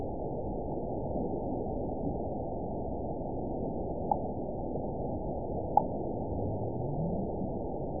event 912569 date 03/29/22 time 14:45:44 GMT (3 years, 1 month ago) score 9.64 location TSS-AB03 detected by nrw target species NRW annotations +NRW Spectrogram: Frequency (kHz) vs. Time (s) audio not available .wav